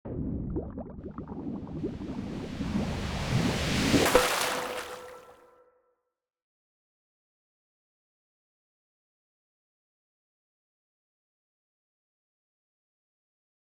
water reload.wav